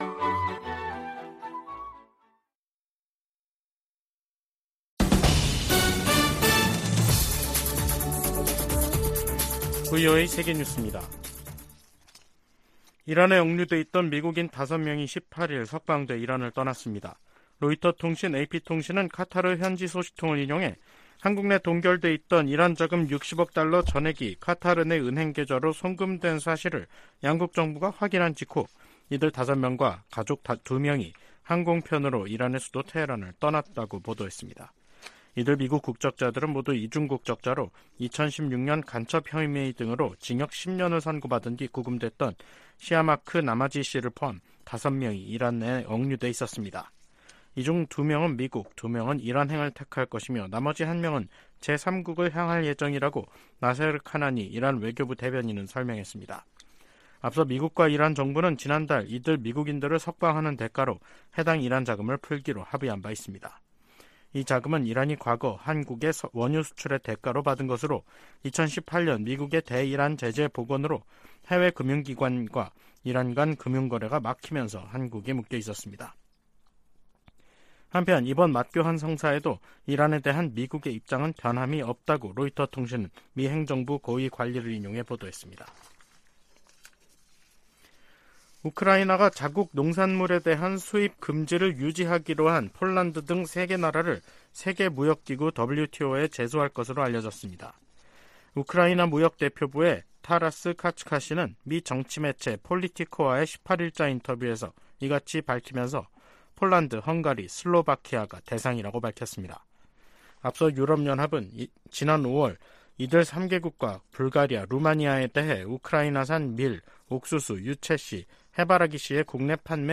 VOA 한국어 간판 뉴스 프로그램 '뉴스 투데이', 2023년 9월 18일 3부 방송입니다. 백악관은 북한과 러시아 사이에 무기 제공 논의가 계속 진전되고 있으며 예의 주시하고 있다고 밝혔습니다. 윤석열 한국 대통령은 북-러 군사협력 움직임에 대해 유엔 안보리 결의에 반한다며 한반도 문제 해결을 위한 중국의 역할을 거듭 촉구했습니다. 우크라이나 주변국들은 북한이 러시아에 우크라이나 전쟁에 필요한 무기를 제공 중이라는 주장에 촉각을 곤두세우고 있습니다.